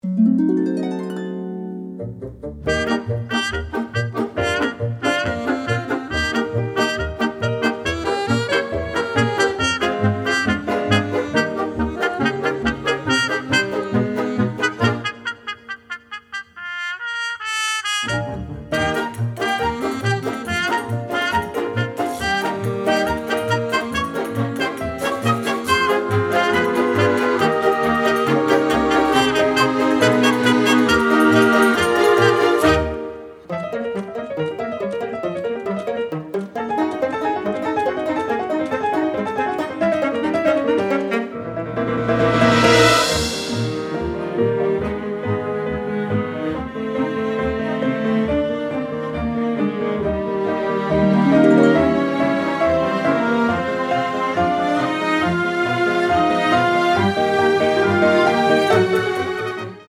The music was recorded in magnificent sound in London